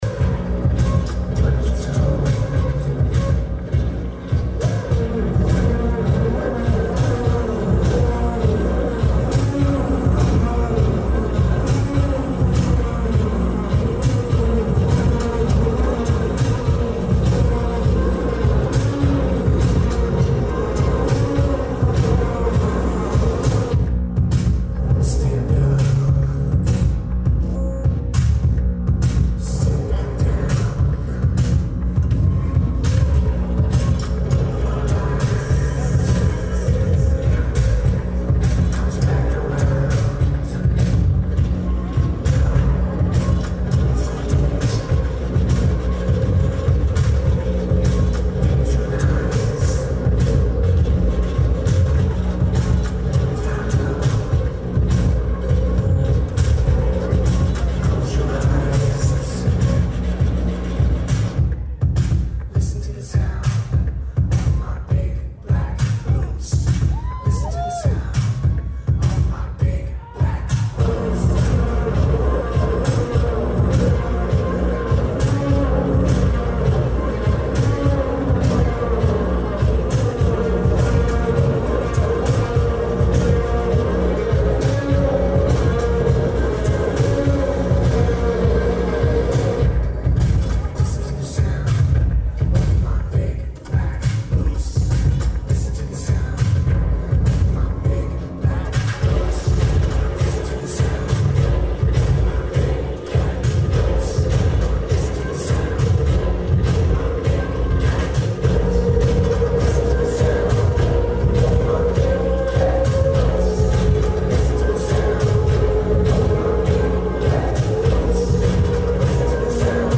Pearl Theatre
Vocals/Guitar/Keyboards
Lineage: Audio - AUD (Tascam Dr-100mkii + Internal Mics)